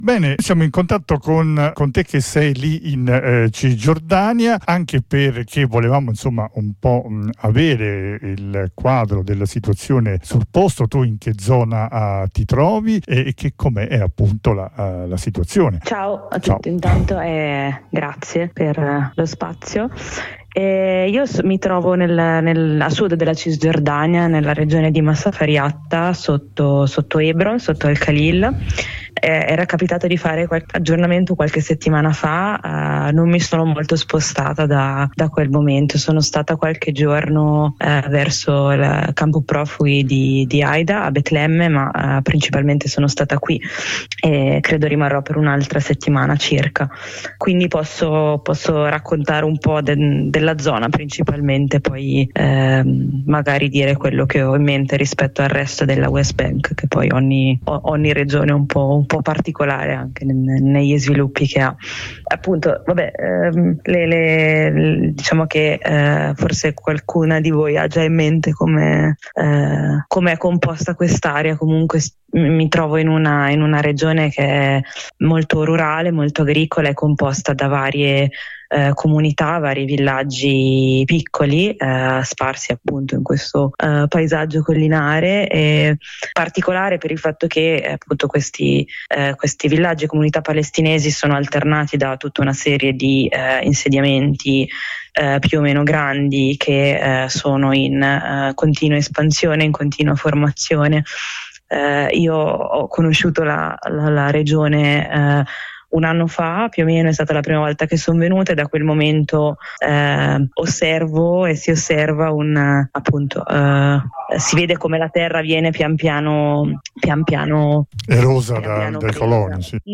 Ne parliamo con una attivista italiana che si trova nella zona di Masafer Yatta ,a sud di Hebron che ci descrive la situazione .